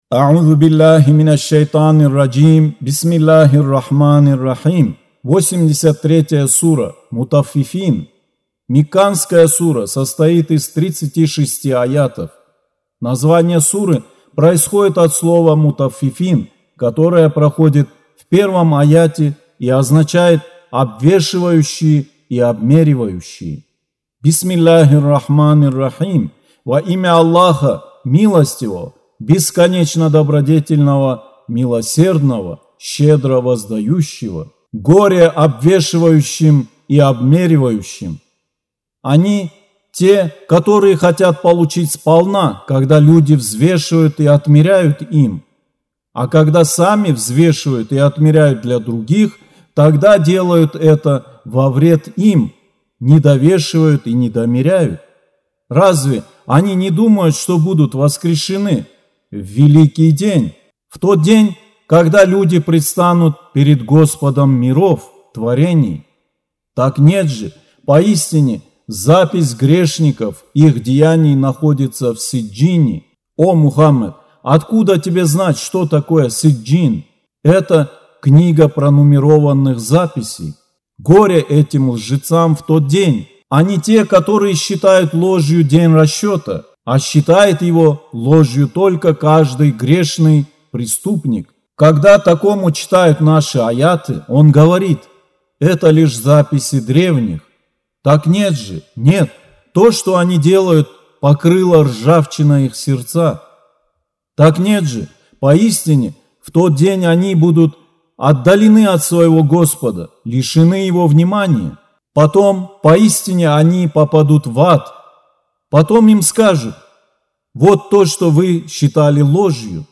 Аудио Коран 83.